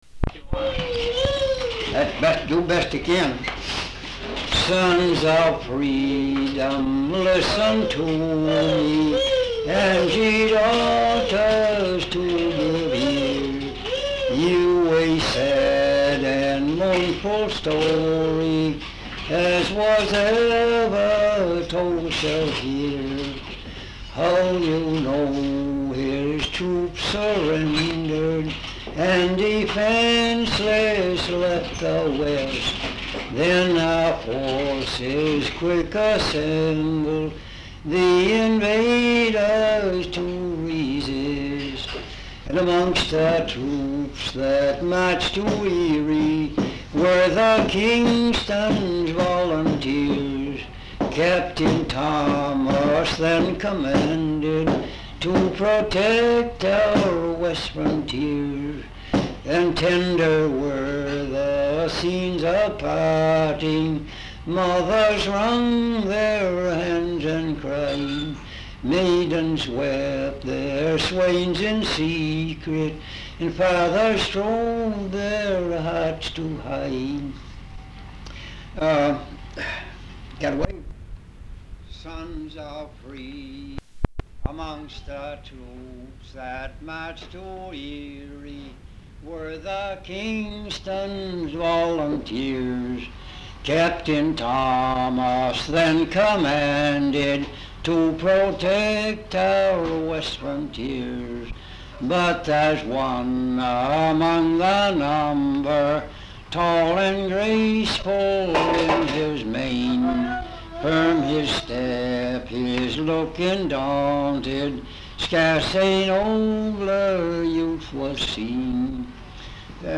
Folk songs, English--Vermont (LCSH)
sound tape reel (analog)